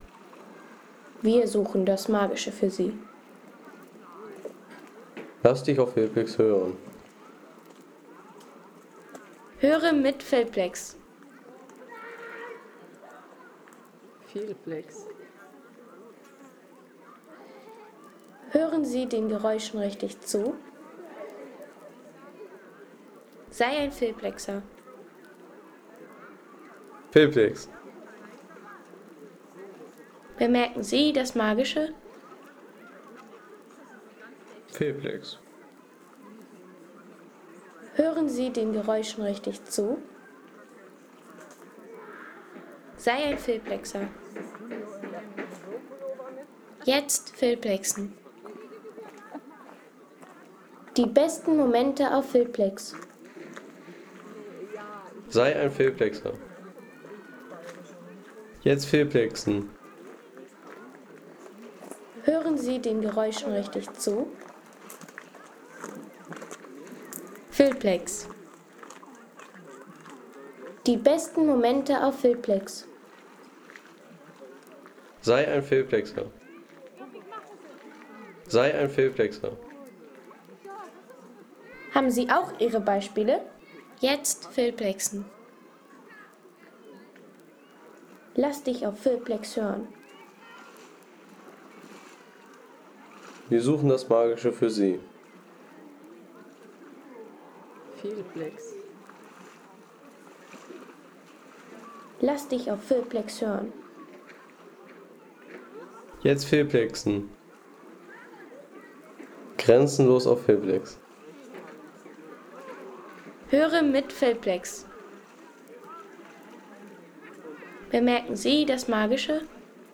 Entspannung am Naturbad Obersee
Landschaft - Bäche/Seen